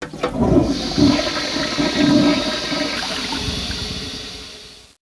FLUSH.WAV